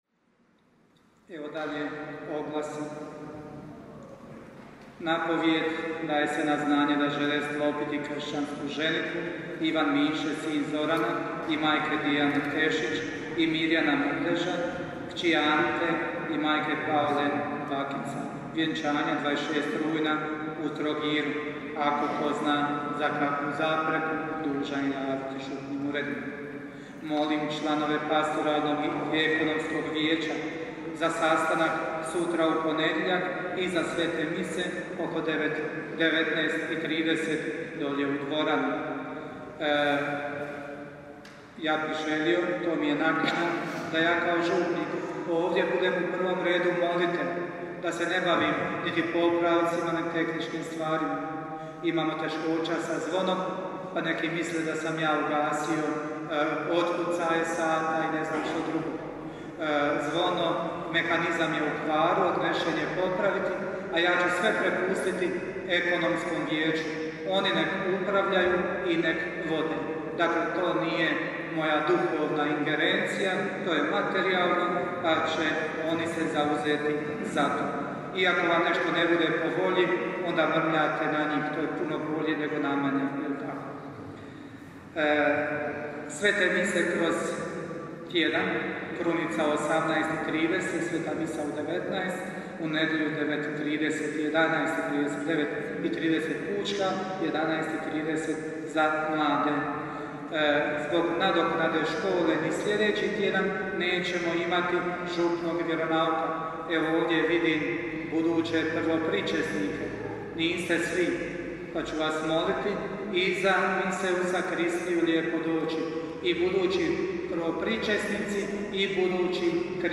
župne obavjesti (oglasi):